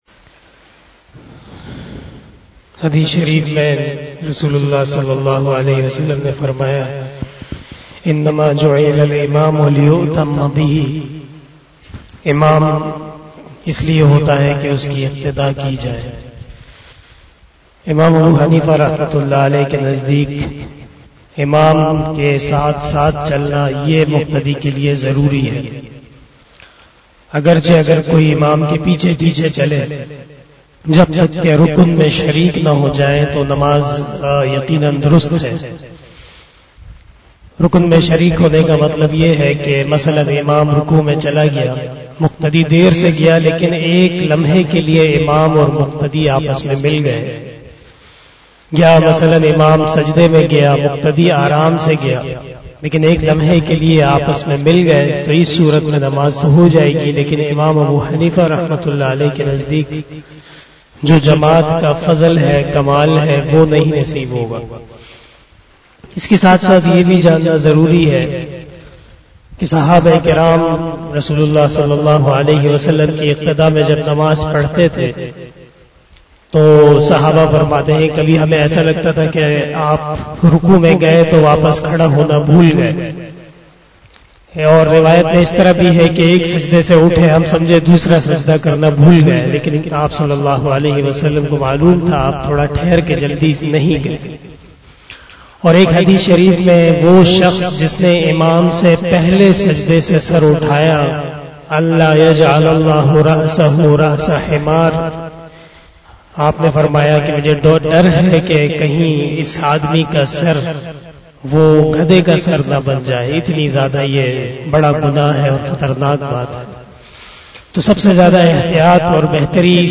067 After Asar Namaz Bayan 09 October 2021 (02 Rabbi ul Awwail 1443HJ) Saturday